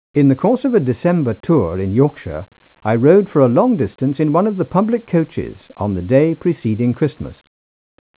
The speech signal is mono, sampled with 8 kHz sampling frequency and quantized to 8 bits/sample (256 levels). hey04_8bit.wav nuit04_8bit.wav speech.wav In Matlab, WAV files can be easily read using the function audioread .
speech.wav